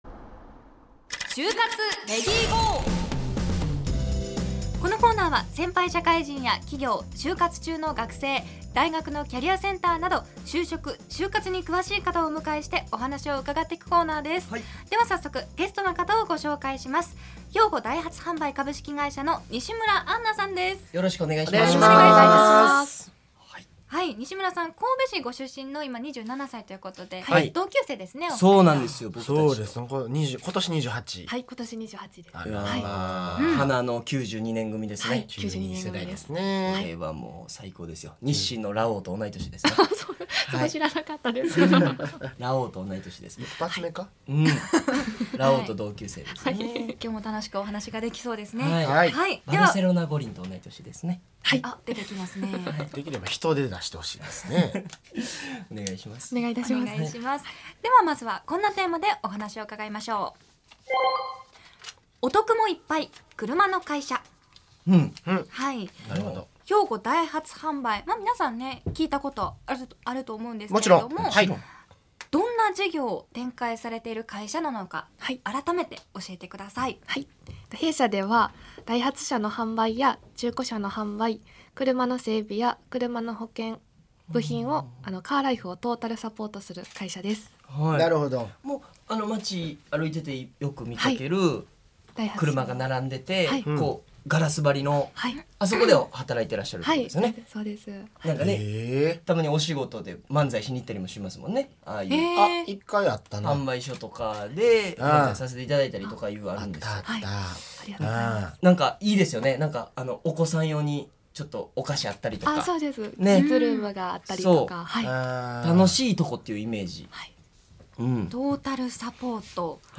就活トークを展開した。